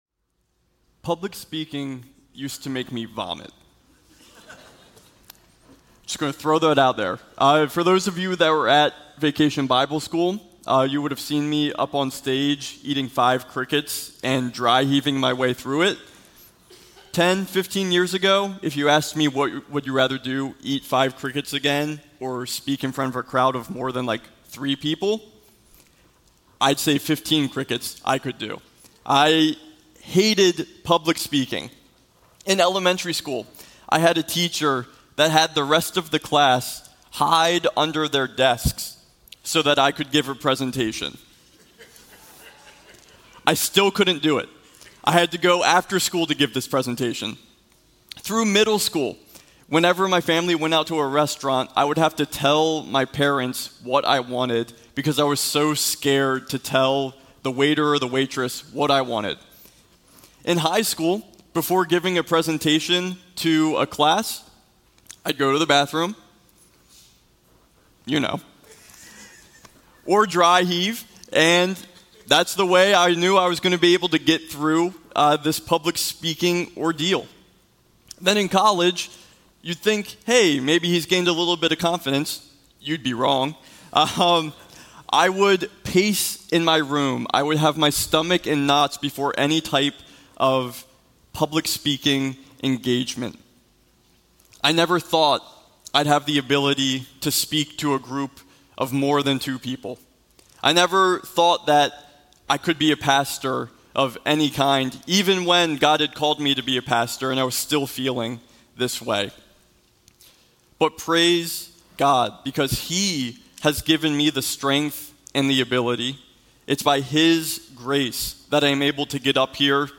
These are sermons delivered during the Sunday morning worship services of Keystone Church, an Evangelical Free Church in Paradise, PA, USA.